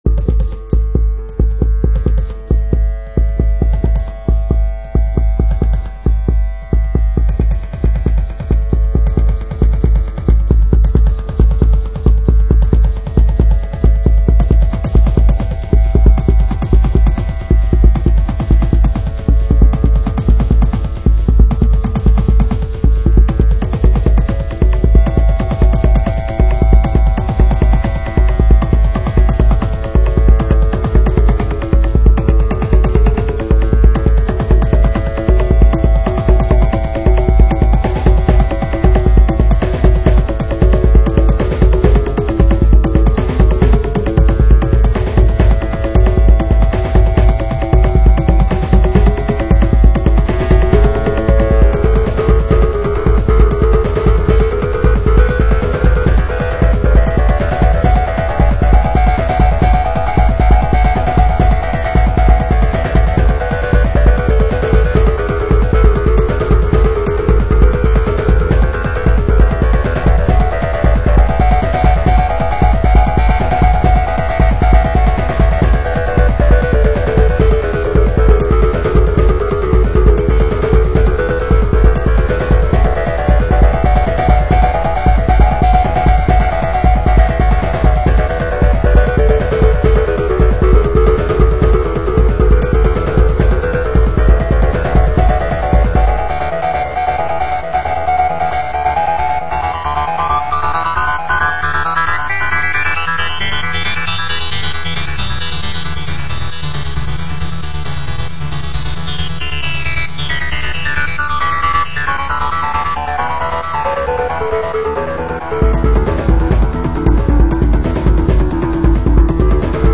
dance/electronic
Summer ambience, at least that's how it sounds to me.